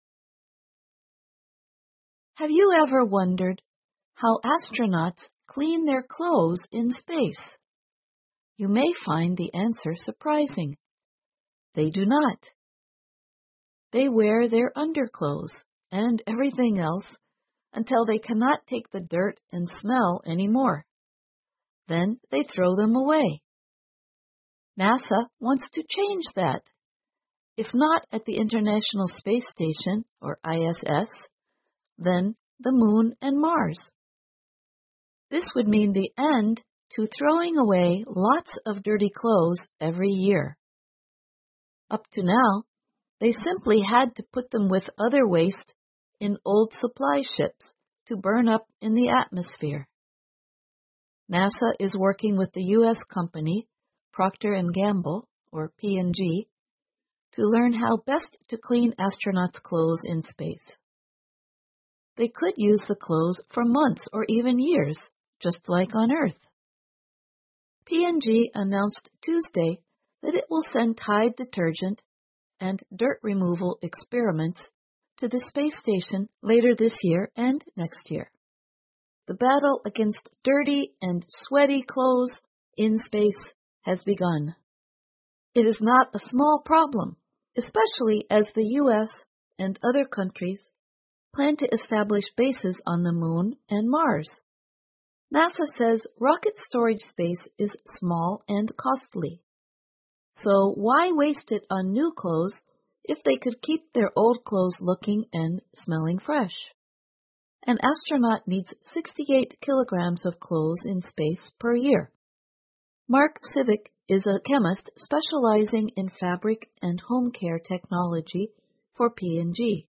VOA慢速英语--宇航员在太空如何清洗衣服？ 听力文件下载—在线英语听力室